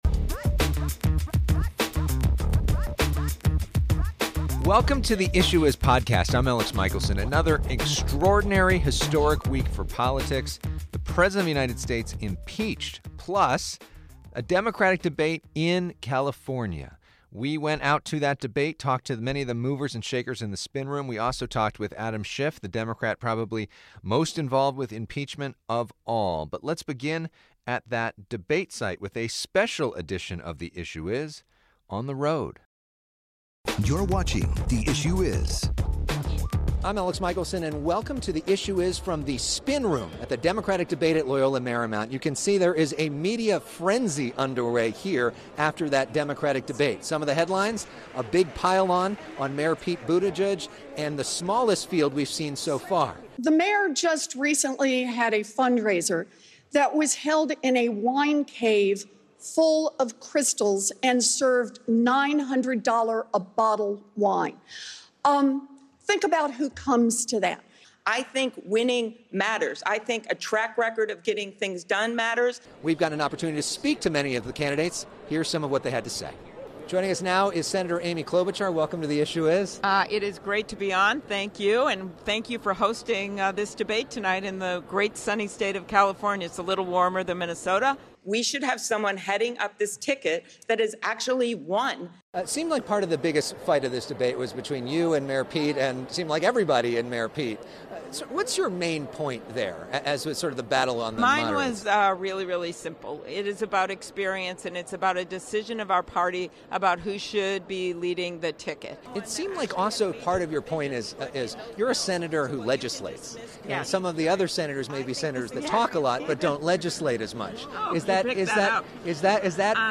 Adam Schiff Episode 115, Dec 21, 2019, 10:41 PM Headliner Embed Embed code See more options Share Facebook X Subscribe A special edition from the spin room of the Democratic Debate at Loyola Marymount in Los Angeles.